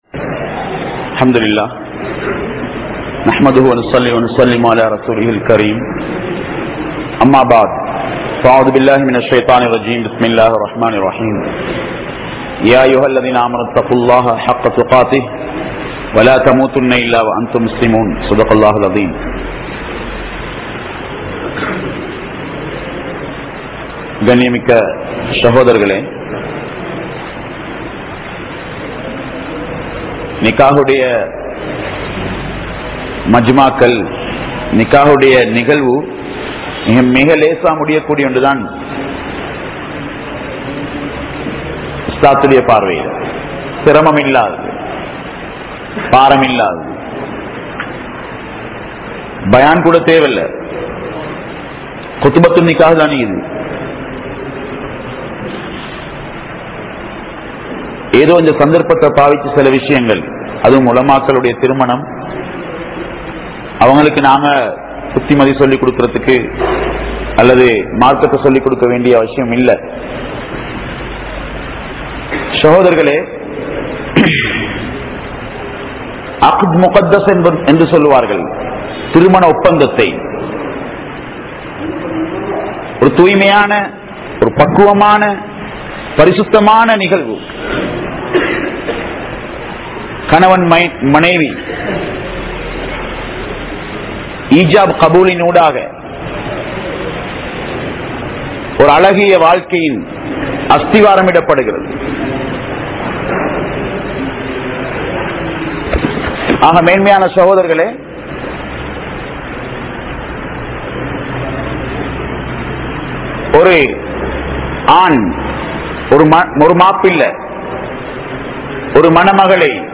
Kaliyaattagalaahum Thirumanagal (கழியாட்டங்களாகும் திருமணங்கள்) | Audio Bayans | All Ceylon Muslim Youth Community | Addalaichenai